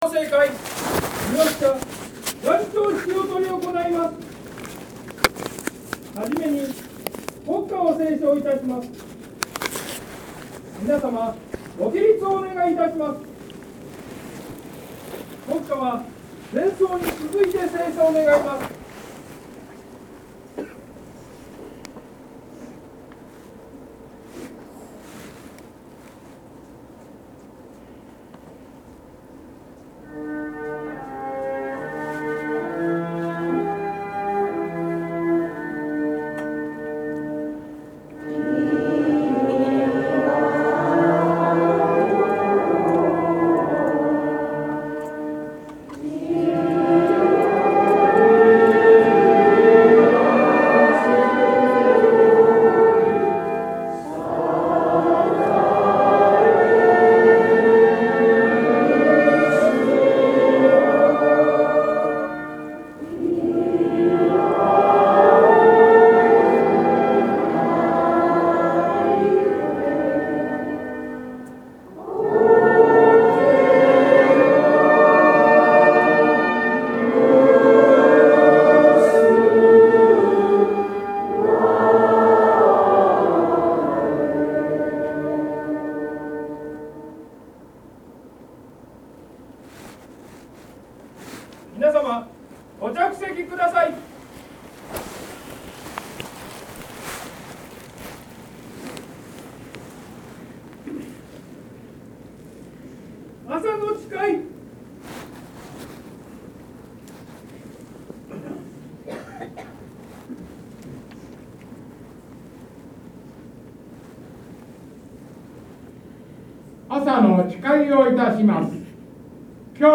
今年の元朝式も町長や衆議院の議員さんは代理者の出席であったが、来賓挨拶は去年よりやや長めで議員議長さんの奮闘を感じた。さて年頭の辞は最初に3年前の災害からの復興が遅々としていることに触れられた。